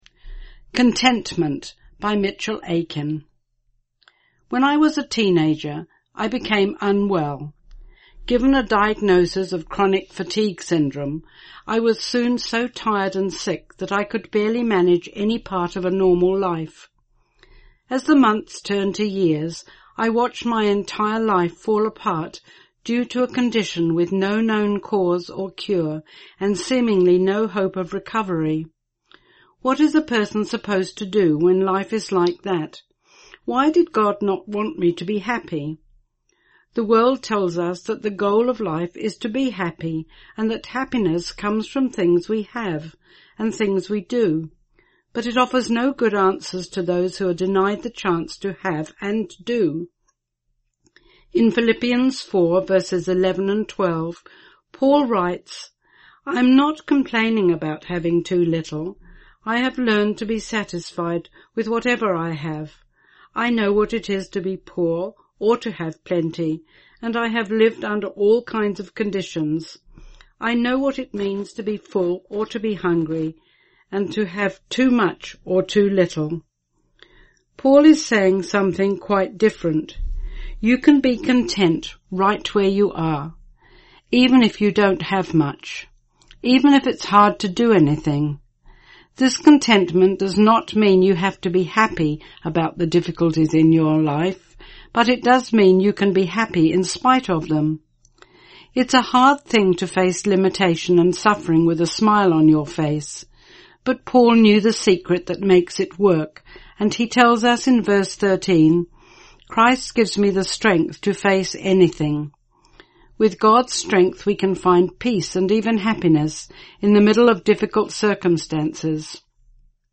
Genre: Speech..